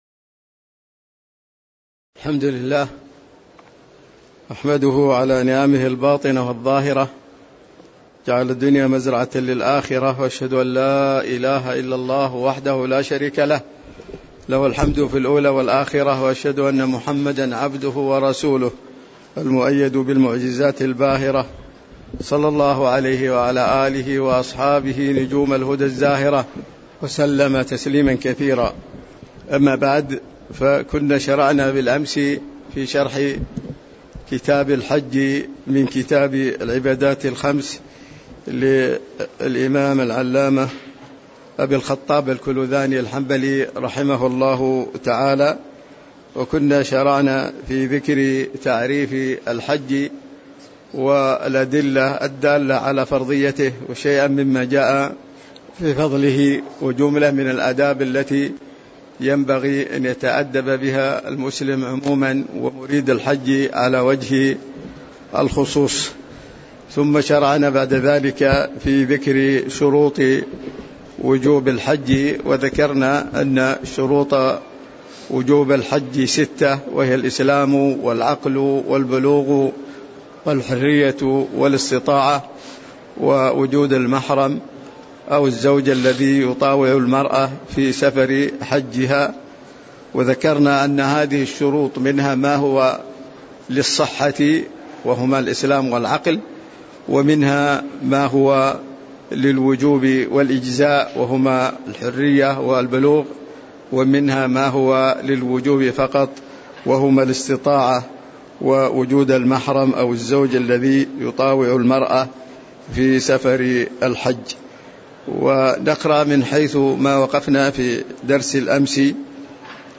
تاريخ النشر ٢٩ ذو القعدة ١٤٤٤ هـ المكان: المسجد النبوي الشيخ